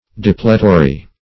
Depletory \De*ple"to*ry\, a.